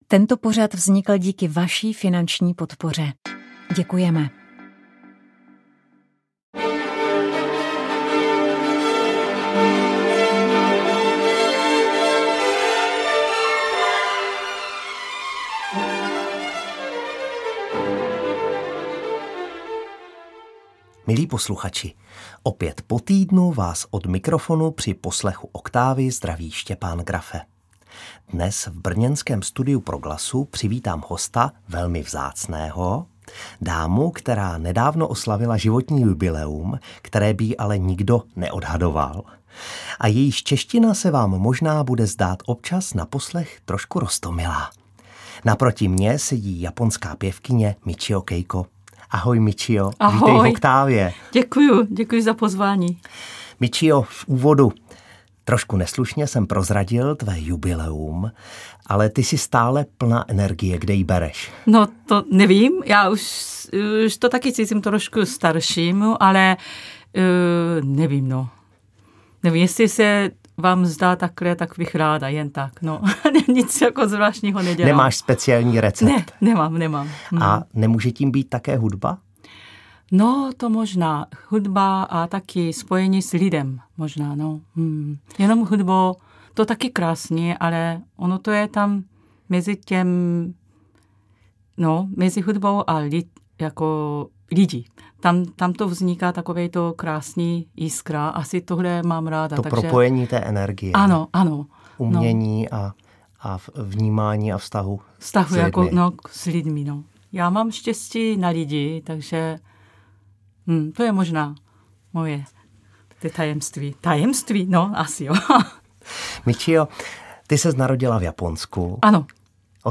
Rozhovor
tematické hudební ukázky natočené pro Proglas v koncertním sále